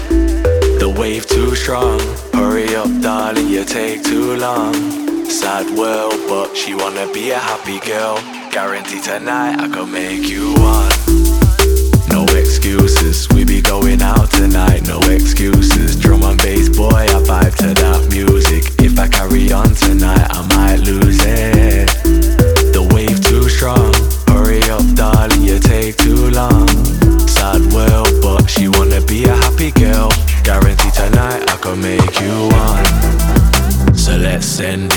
2022-04-28 Жанр: Танцевальные Длительность